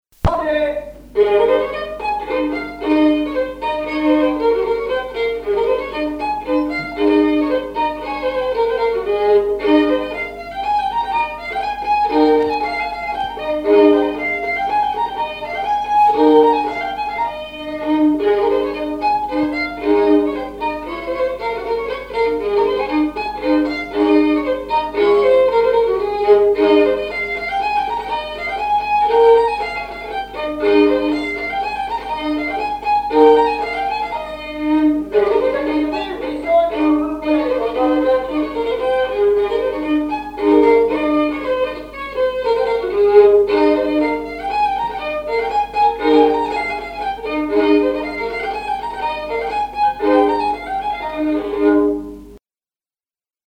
danse : branle : avant-deux
violoneux
Pièce musicale inédite